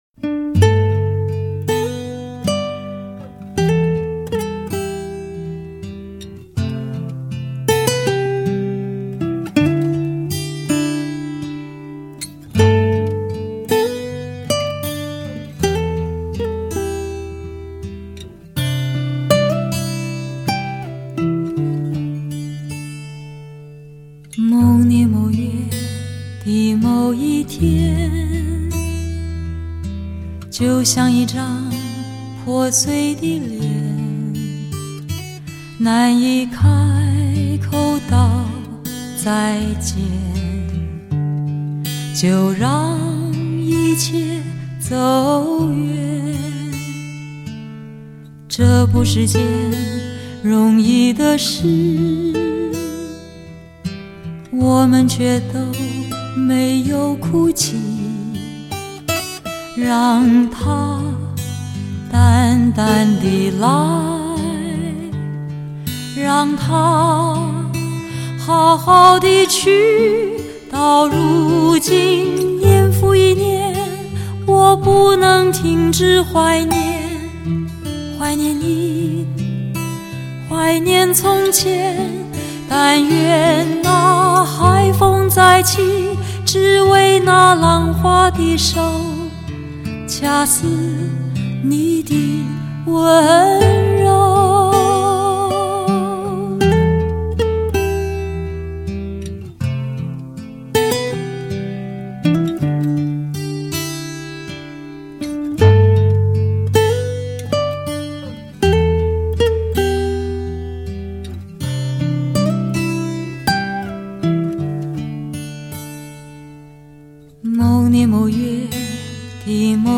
汽车发烧音乐HIFI典范专辑